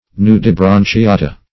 Search Result for " nudibranchiata" : The Collaborative International Dictionary of English v.0.48: Nudibranchiata \Nu`di*bran`chi*a"ta\, n. pl.
nudibranchiata.mp3